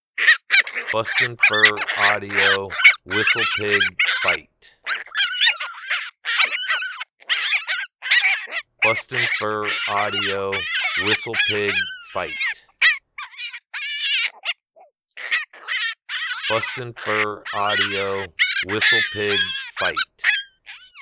Juvenile groundhogs fighting over food.
BFA Whistle Pig Fight Sample.mp3